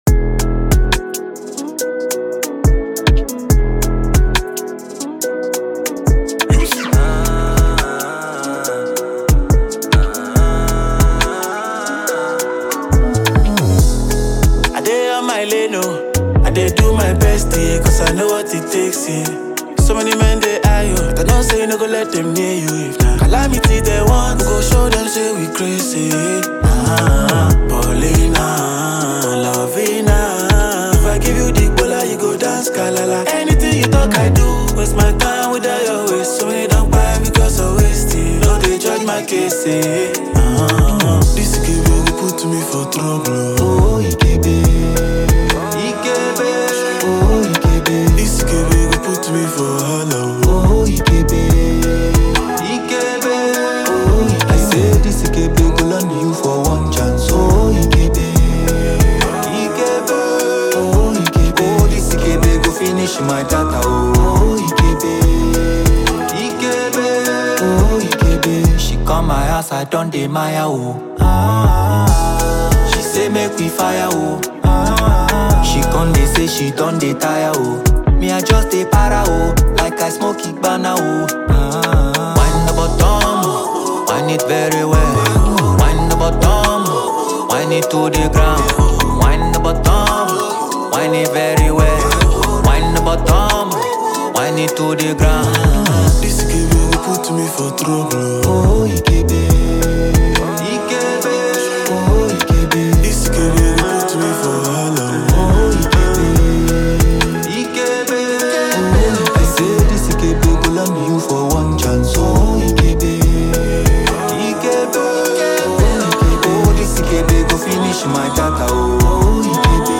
Nigerian Afrobeats superstar
infectious tune